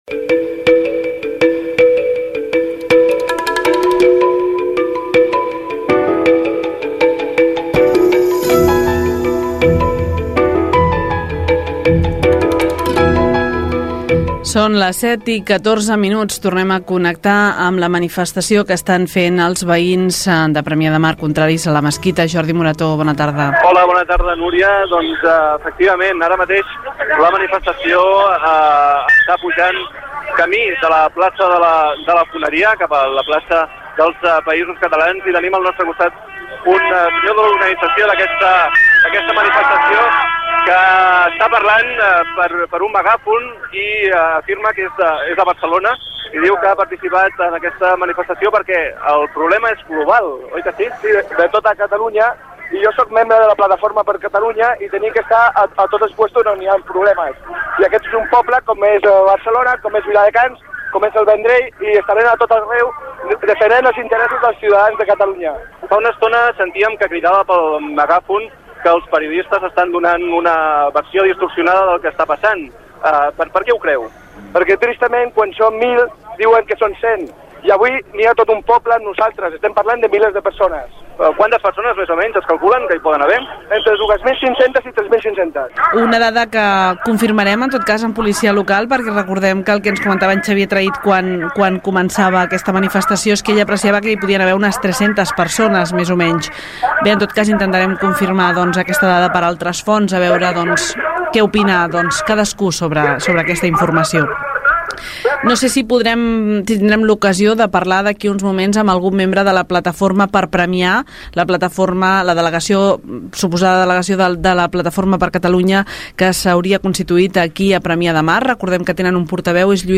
Informatius: manifestació contra la mesquita - Ràdio Premià de Mar, 2002